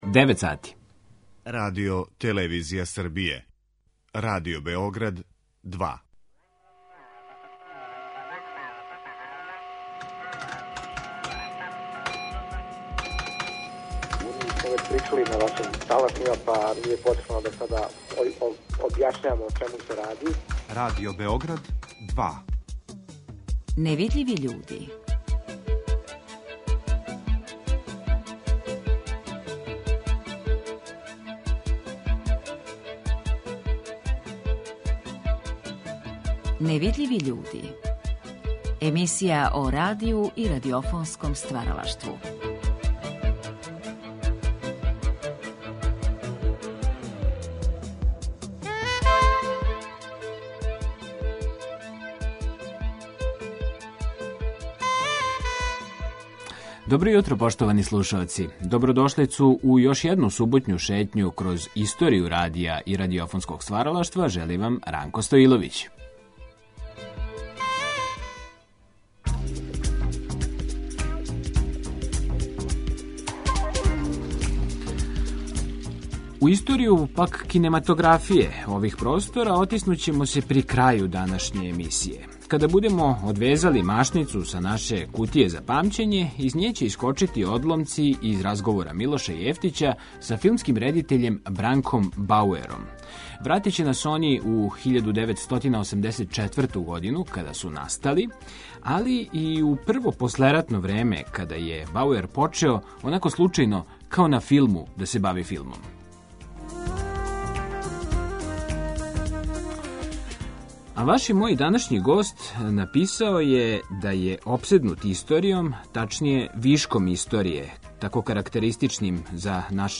Тешко је направити репрезентативан избор из његовог великог стваралачког опуса, али, ми ћемо се звуком прошетати од прве радио-драме (Свиња, 1971), преко оних које су освајале најзначајније награде на међународним фестивалима (Мислио сам да се руше брда, Prix Italia, 1979), до чудесних атрактивних минијатура и остварења за децу (Змај у подруму, 1984).